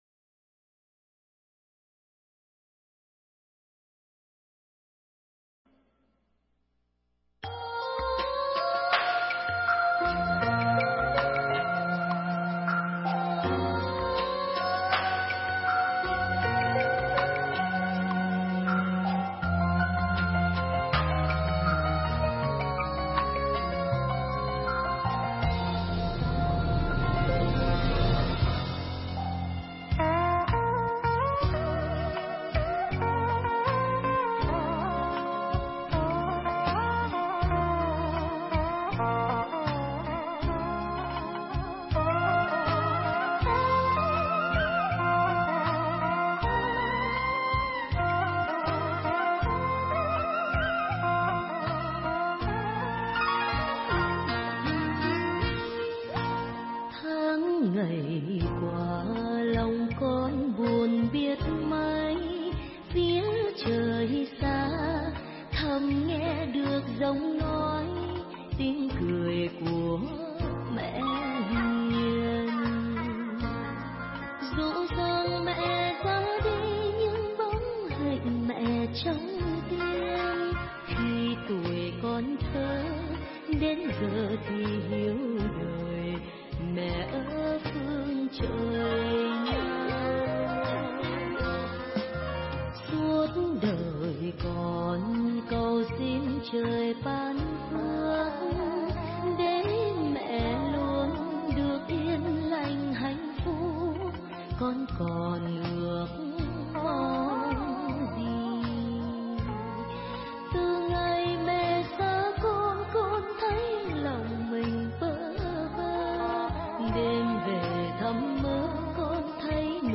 thuyết pháp